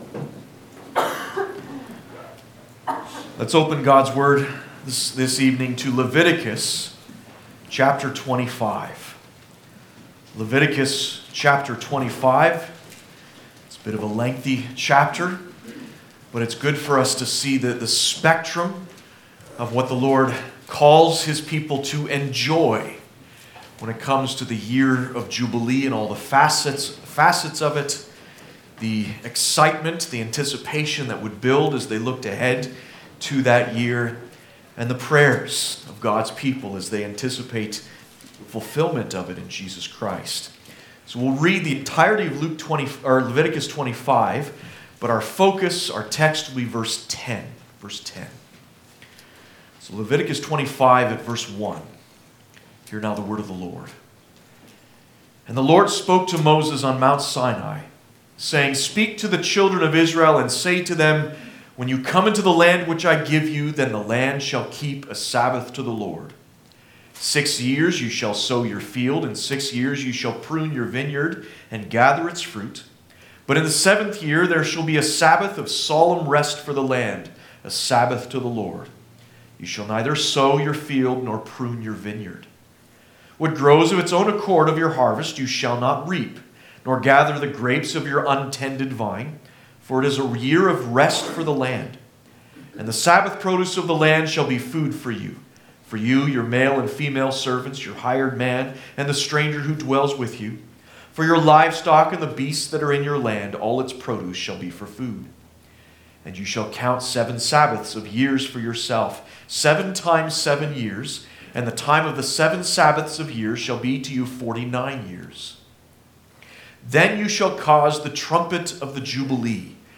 Service Type: Prayer Service